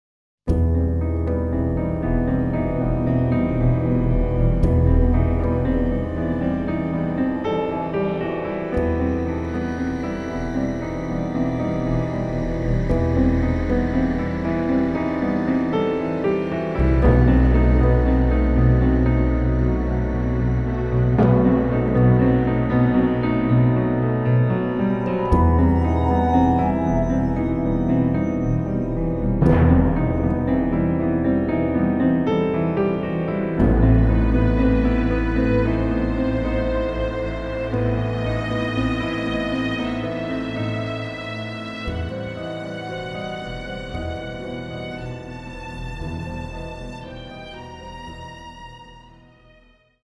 nervous string patterns. The recording features live strings
piano solos
timpani
synths and additional piano parts